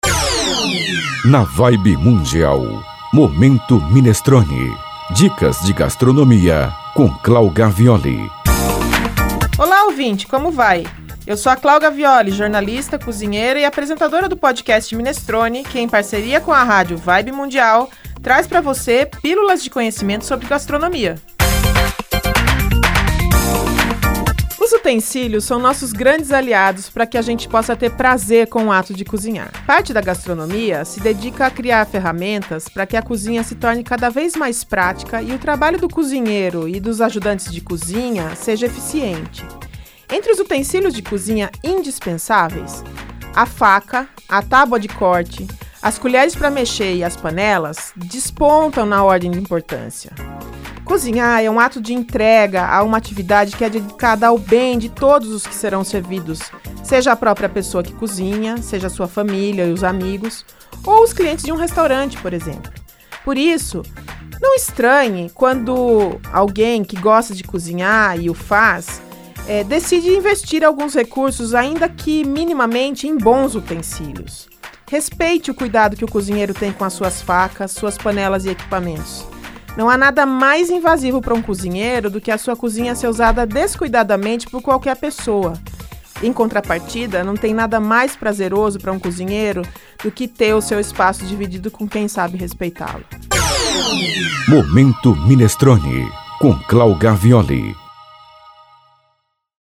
Três vezes ao dia, durante a programação da rádio (às 7h25, 14h25 e 22h25), são veiculadas pílulas de conhecimento em gastronomia.